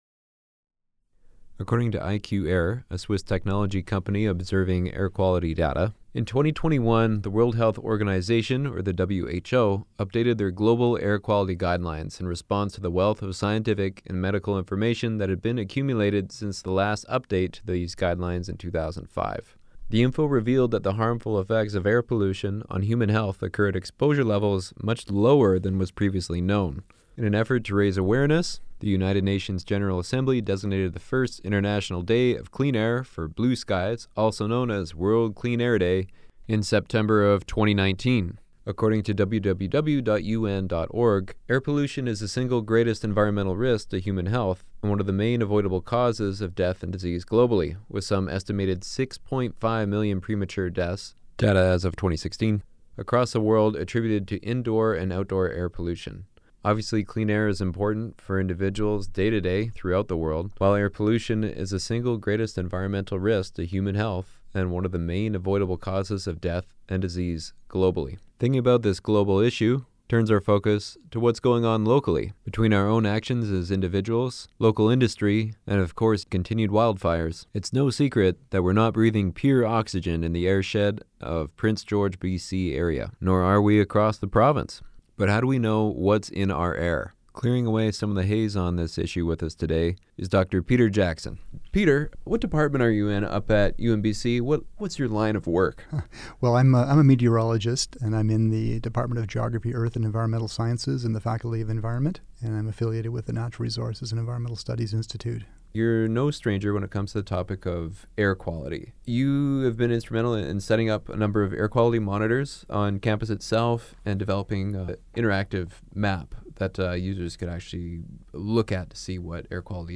and Environmental Sciences speaks to CFUR about Air Quality, the methods used to monitor it, and how to understand data provided by a number of websites.